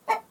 angrycluck1.wav